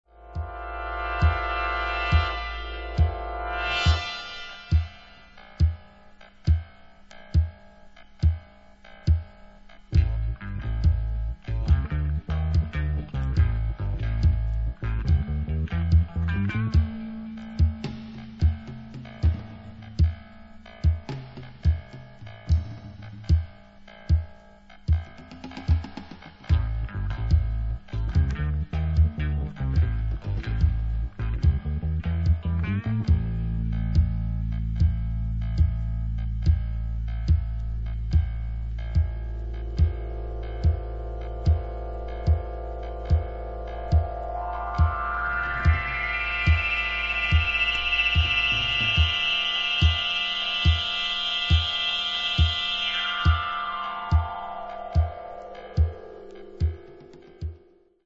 This track is all about suspense.
Great synth.